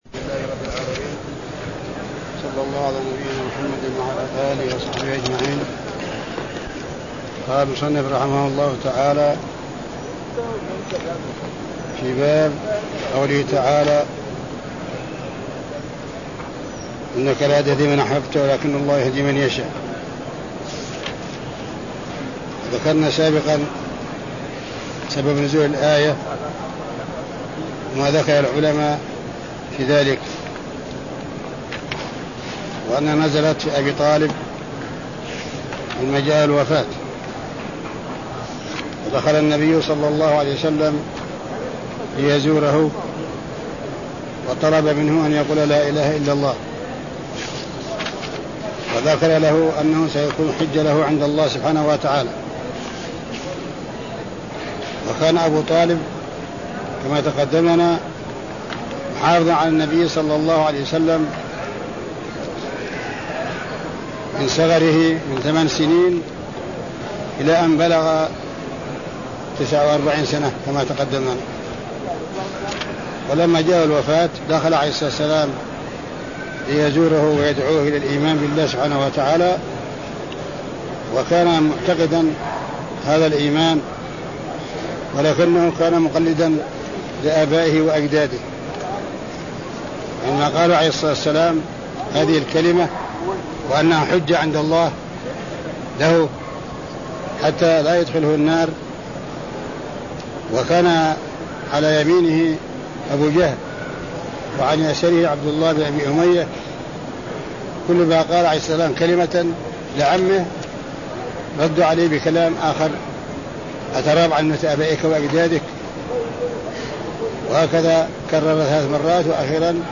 تاريخ النشر ٨ جمادى الأولى ١٤٤٧ المكان: المسجد النبوي الشيخ